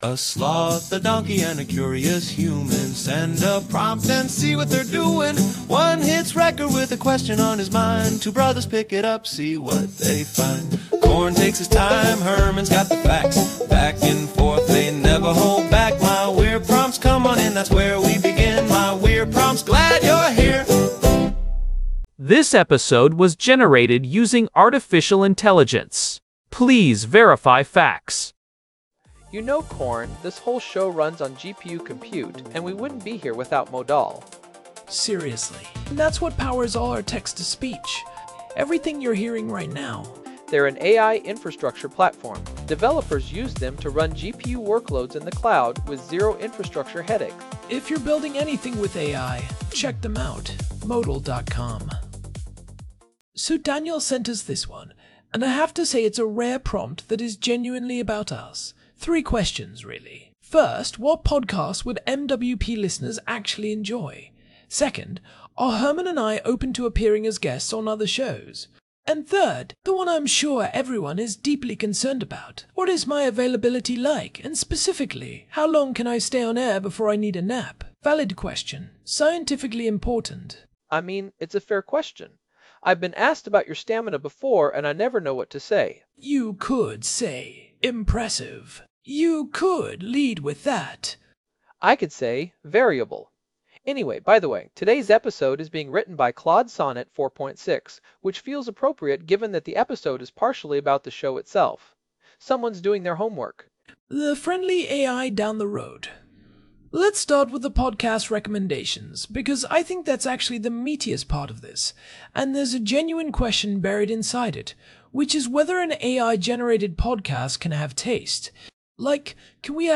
Two AI hosts curate 12 podcasts for curious minds—and ask whether an AI can actually have taste in the first place.
AI-Generated Content: This podcast is created using AI personas.